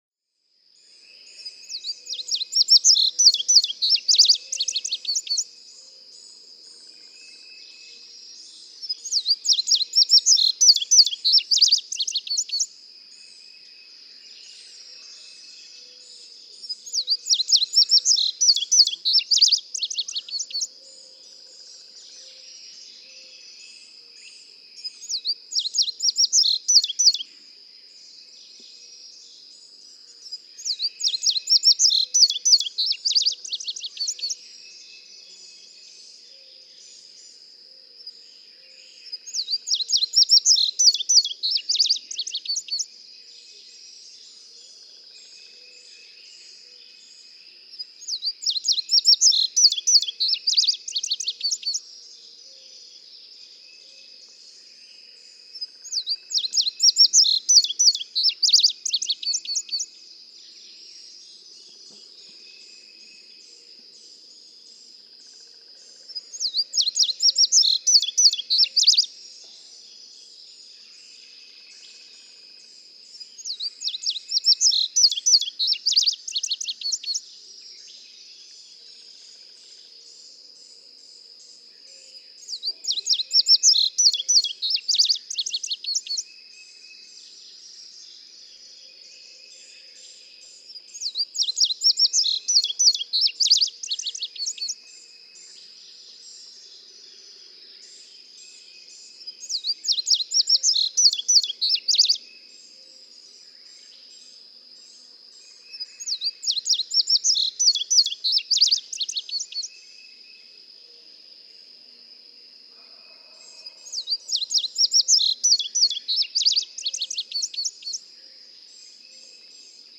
Francis Marion National Forest, South Carolina.
♫207, ♫208—longer recordings from those two neighbors
208_Indigo_Bunting.mp3